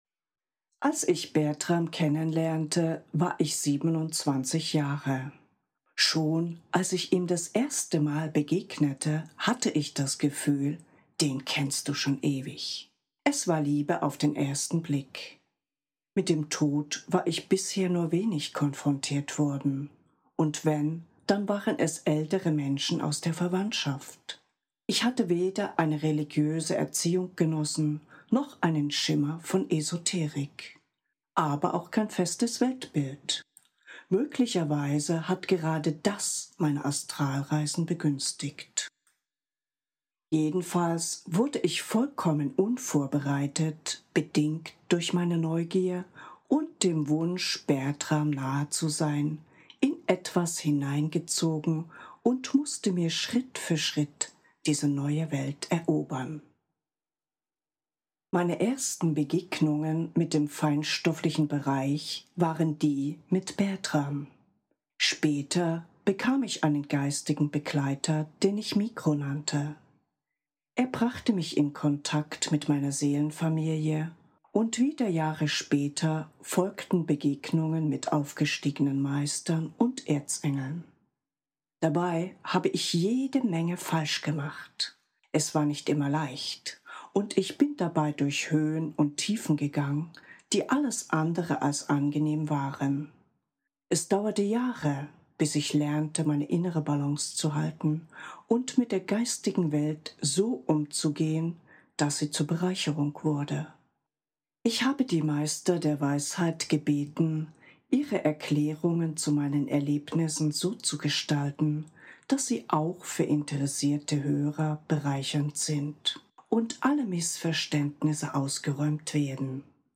Audio/Hörbuch 2013